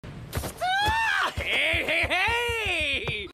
bokuto-saying-hey-hey-hey-sub.mp3